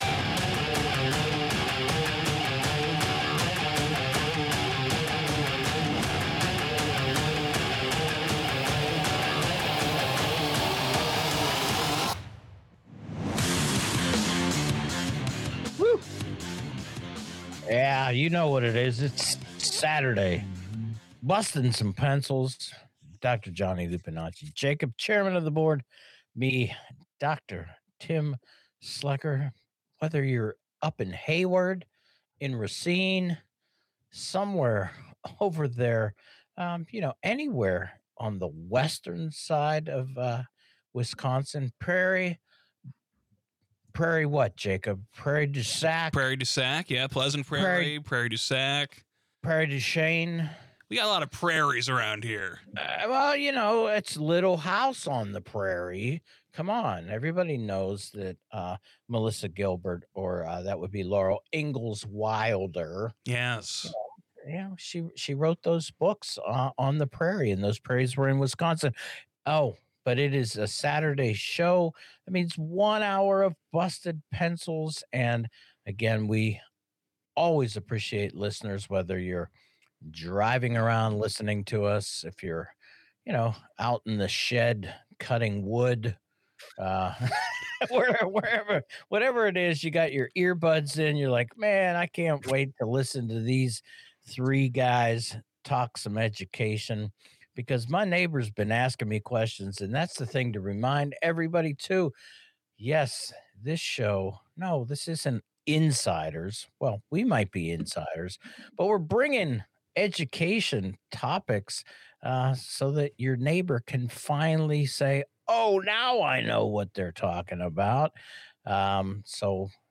channels George W. Bush during this episode.